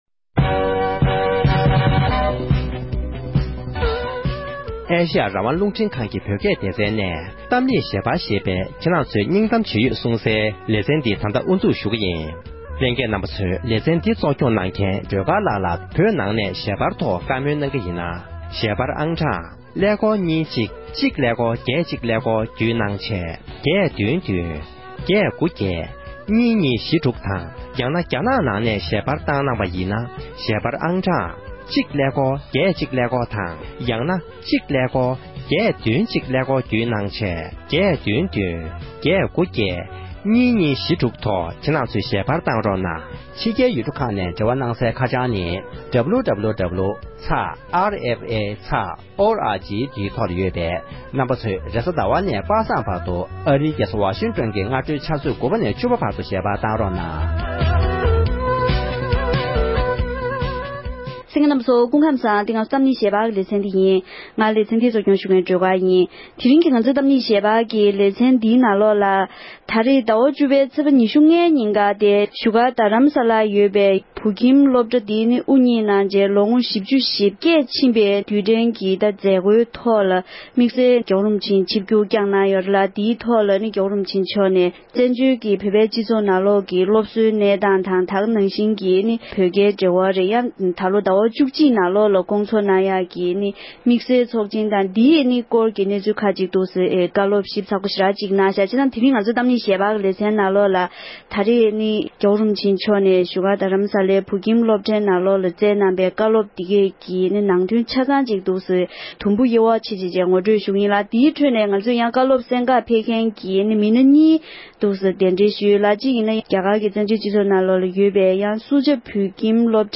རྡ་ས་བོད་ཁྱིམ་དབུ་བརྙེས་ནས་མི་ལོ་ཞེ་བརྒྱད་འཁོར་བའི་མཛད་སྒོའི་སྐབས་༸གོང་ས་མཆོག་ནས་བསྩལ་བའི་གསུང་འཕྲིན།